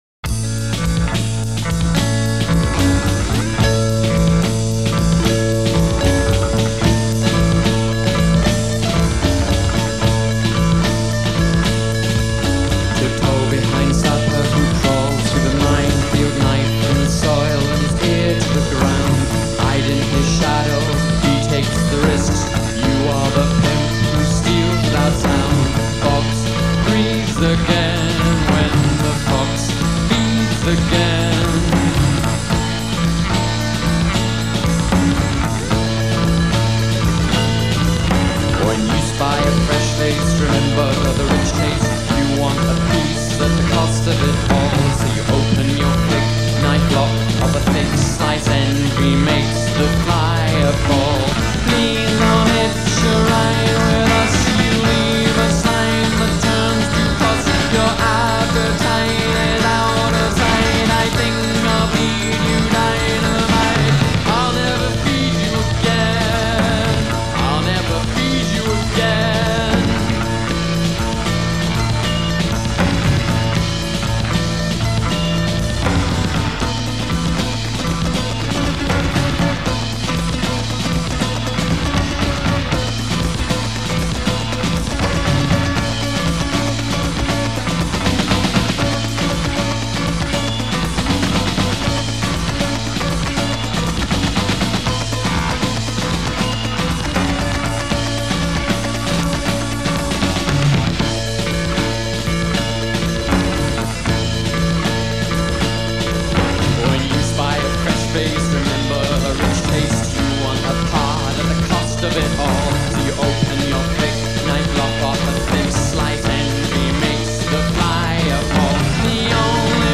Alternative/Post- punk band